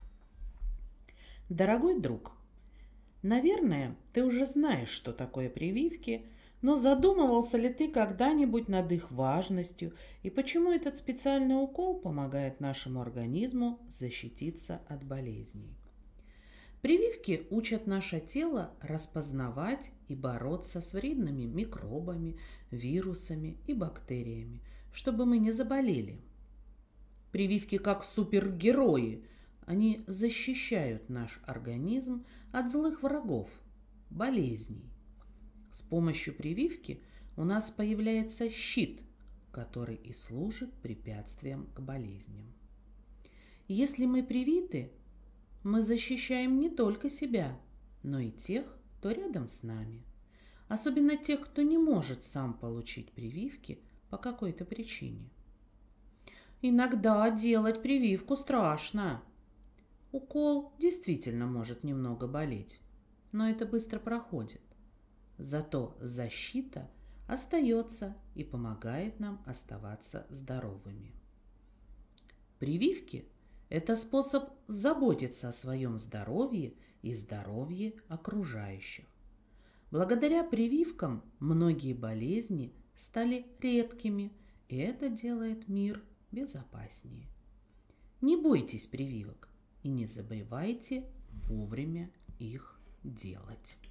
Аудиорассказ Почему прививки так важны